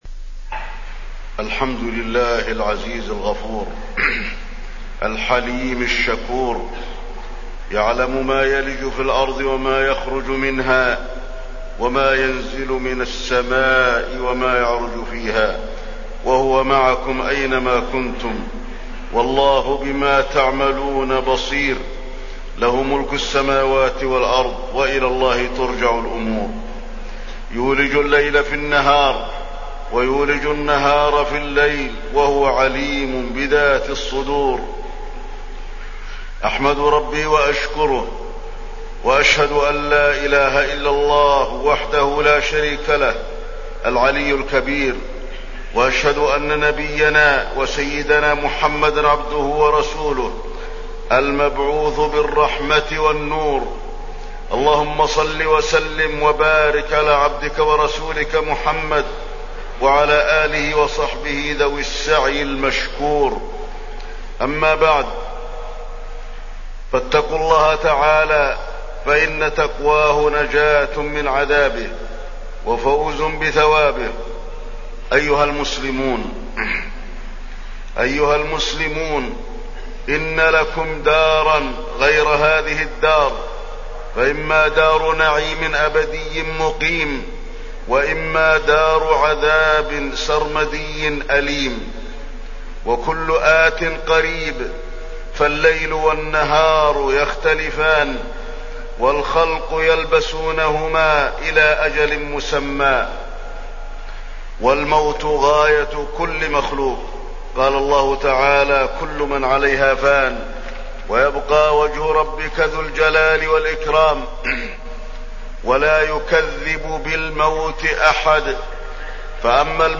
تاريخ النشر ٣ شوال ١٤٢٩ هـ المكان: المسجد النبوي الشيخ: فضيلة الشيخ د. علي بن عبدالرحمن الحذيفي فضيلة الشيخ د. علي بن عبدالرحمن الحذيفي طاعة الله عز وجل